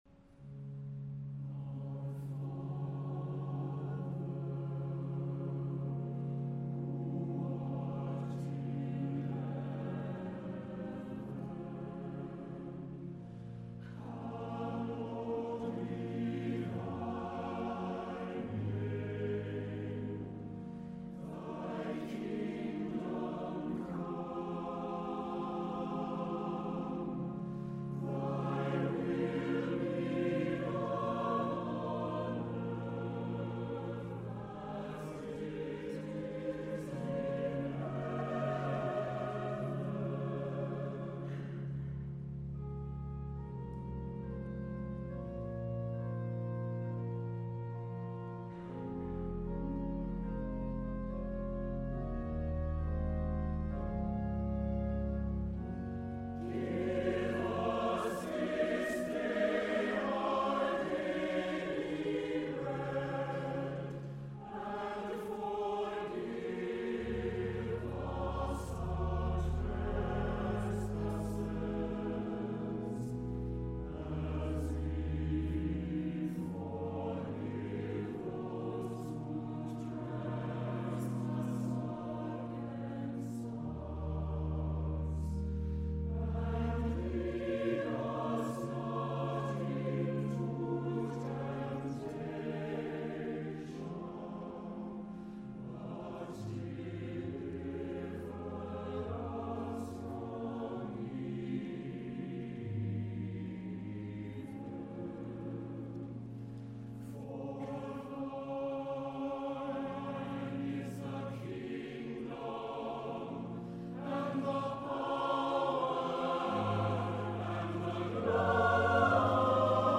for chorus, soprano, tenor, and baritone solos, organ, flute
or keyboard and SATB chorus.